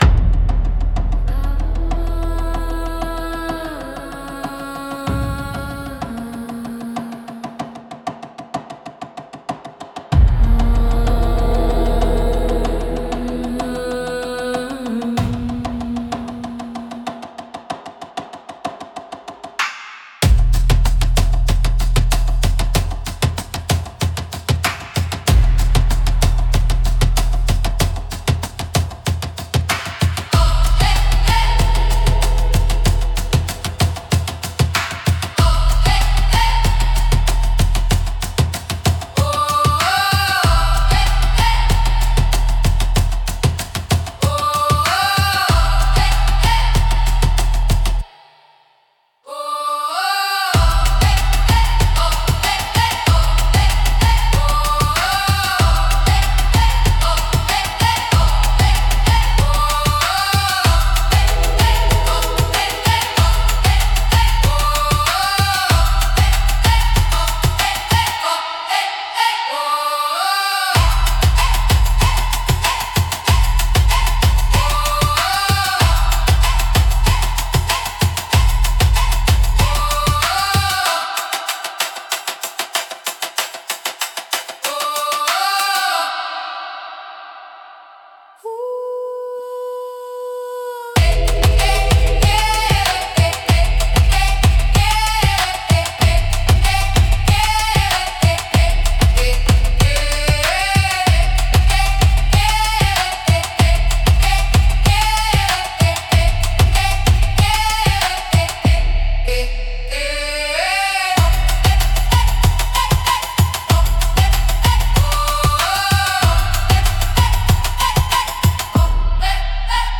オリジナルのトライバルは、民族的な打楽器やリズムが中心となり、神秘的で原始的な雰囲気を持つジャンルです。
繰り返しのリズムと独特のメロディが、古代的かつエネルギッシュな空気感を作り出します。
迫力と神秘性が共存するジャンルです。